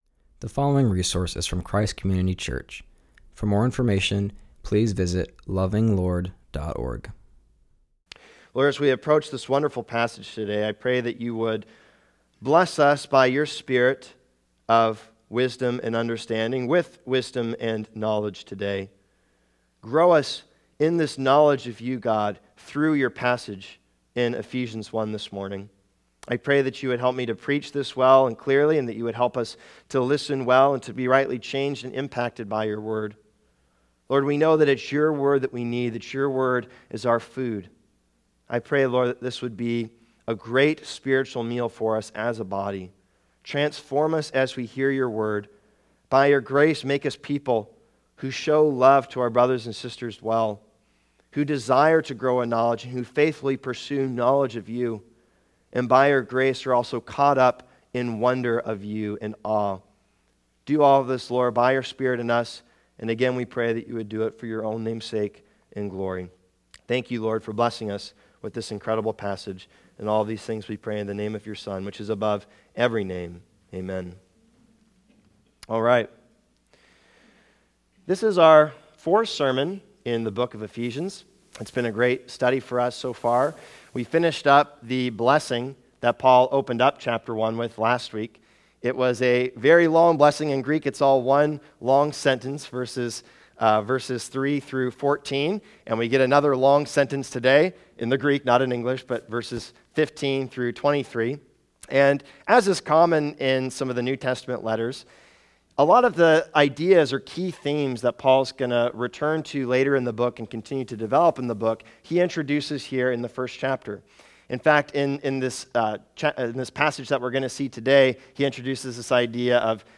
continues our series and preaches from Ephesians 1:15-23.